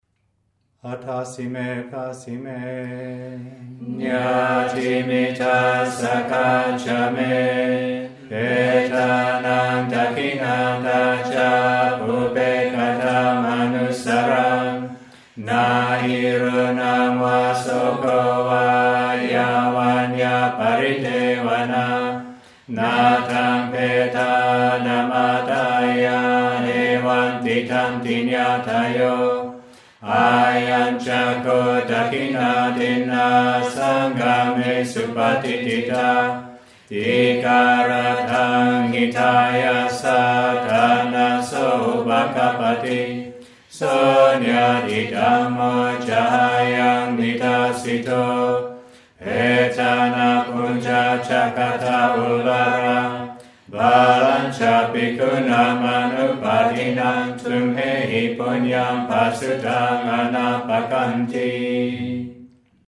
» Pali-English Chanting